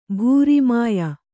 ♪ bhūri māya